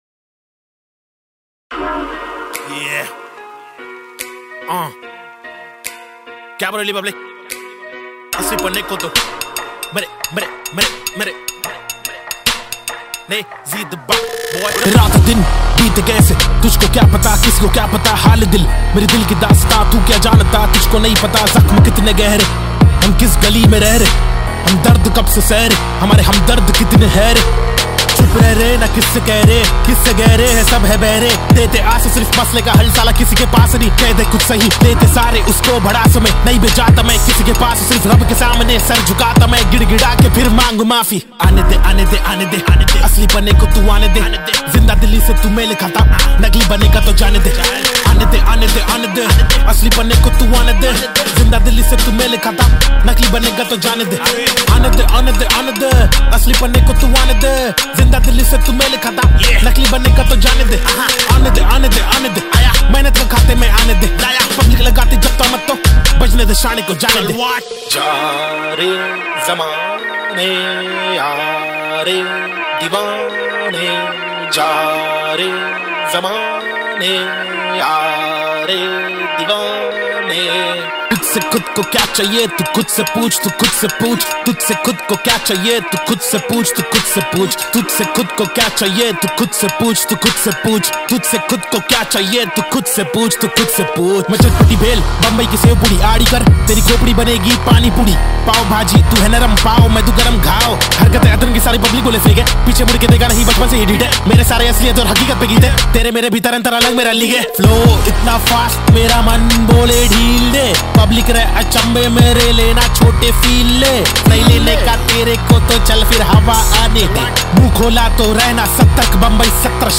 Pop Songs